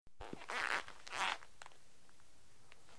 Rubber Squeaks, Like A Tennis Shoe Close Up, X3